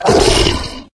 hit_1.ogg